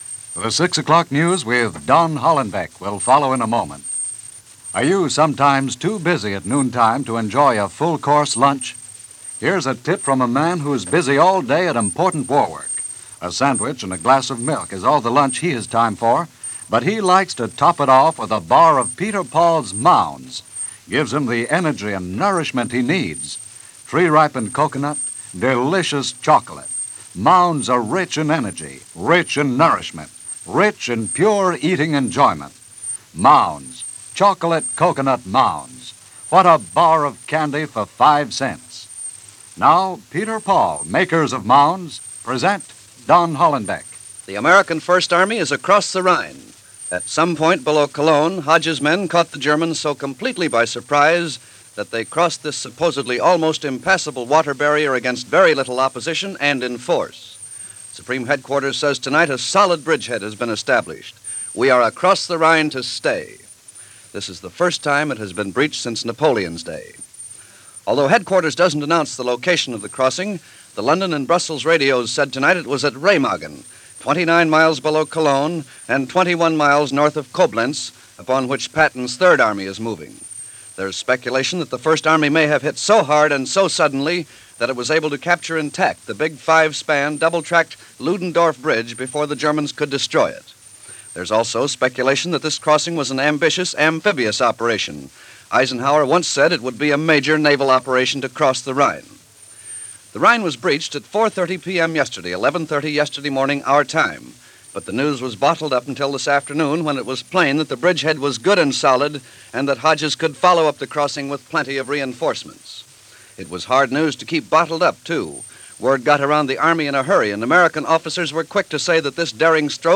6 O’Clock News – WEAF/NBC – March 8, 1945 –